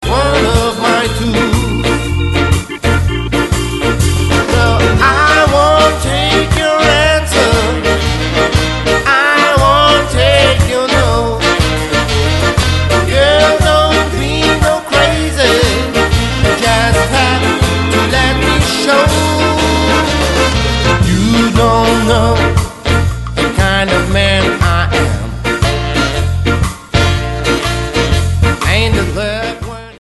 Ska going Zeitgeist
Der Beat treibt wie eh und je.